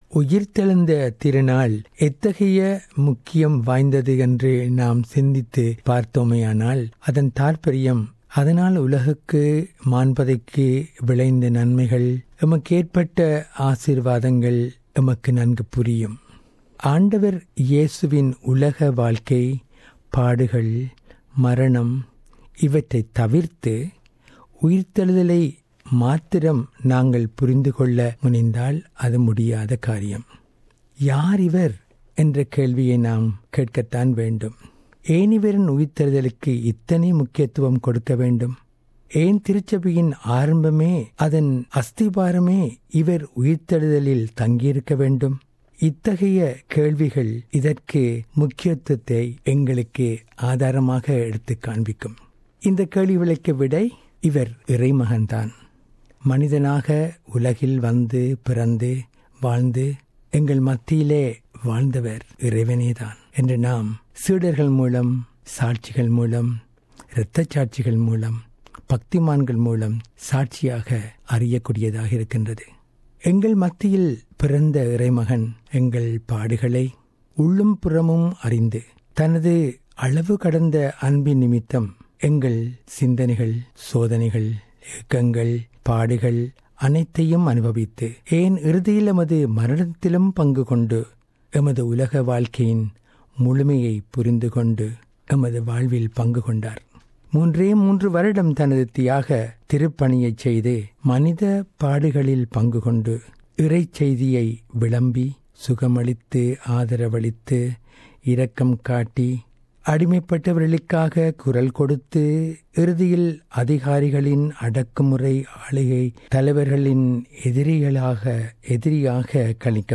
Easter Special Message